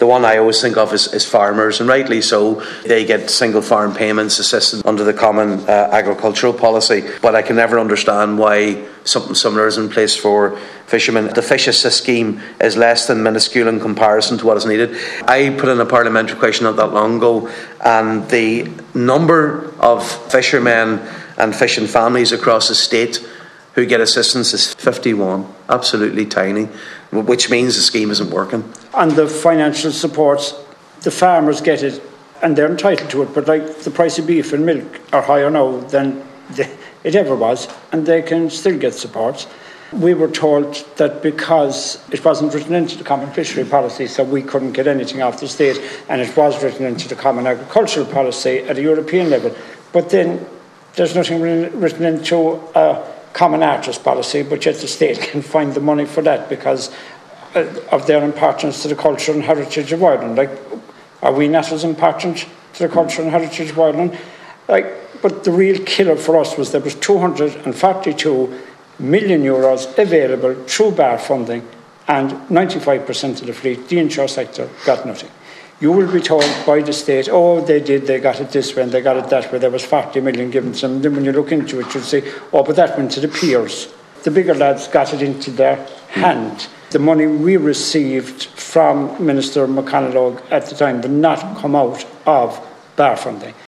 The Oireachtas Fisheries Committee has been told that fishers have been constantly denied financial supports from Europe on the basis that there is no provision for them in the Common Fisheries Policy, but there are supports available for other sectors such as artists.